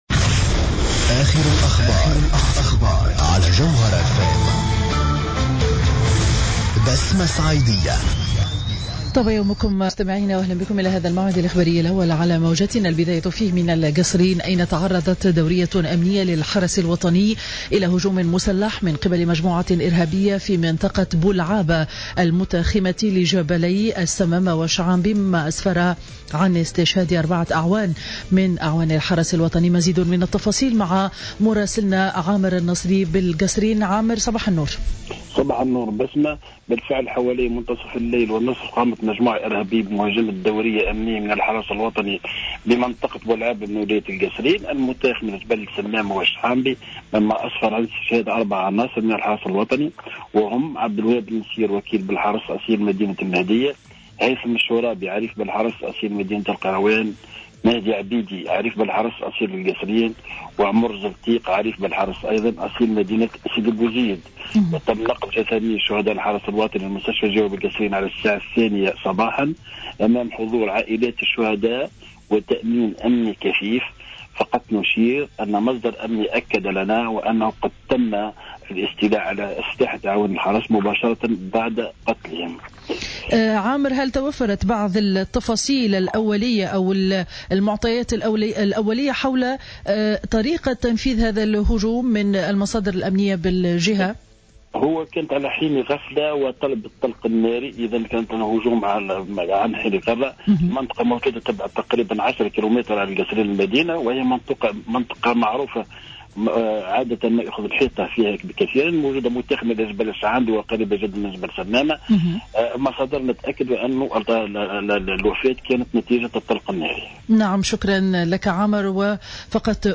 نشرة أخبار السابعة صباحا ليوم الاربعاء 18 فيفري 2015